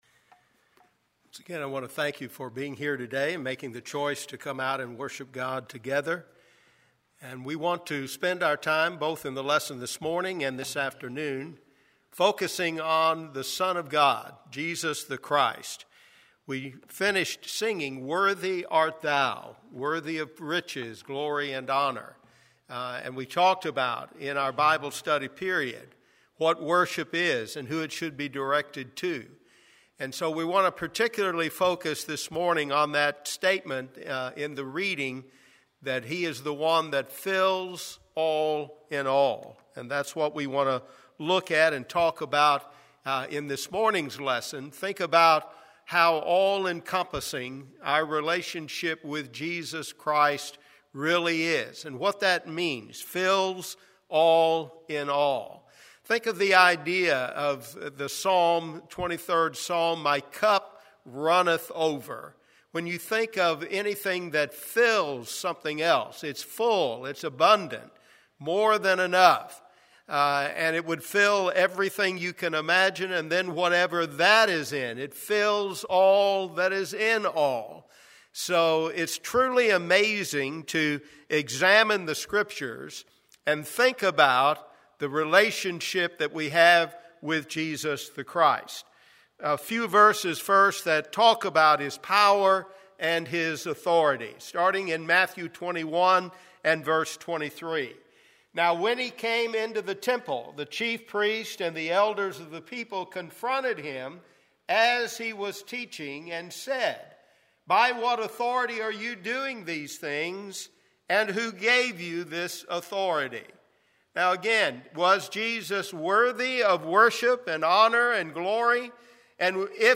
Audio-Sermons 2014